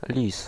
Ääntäminen
Synonyymit mesquakie goupil briseur de grève Ääntäminen France (Paris): IPA: [ɛ̃ ʁə.naʁ] Tuntematon aksentti: IPA: /ʁə.naʁ/ Haettu sana löytyi näillä lähdekielillä: ranska Käännös Ääninäyte Substantiivit 1. lis {m} Suku: m .